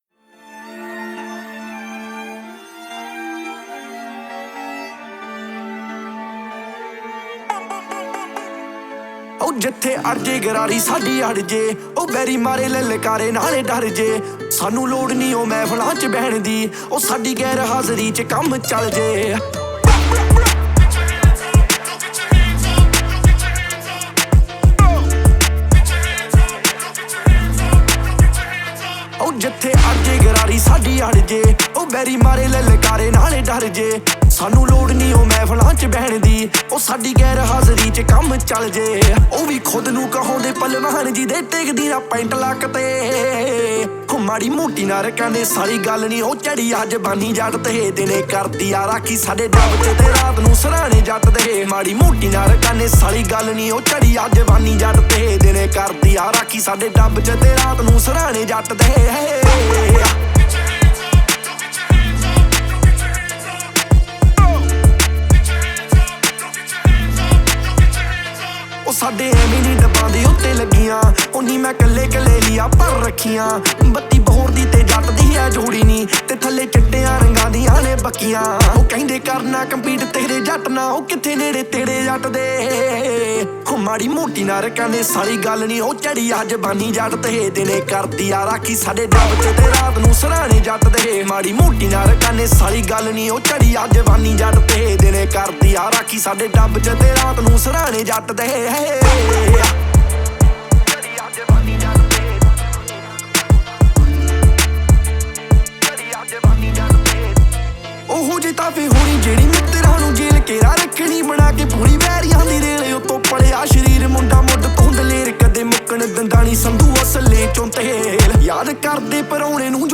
Category: Punjabi Album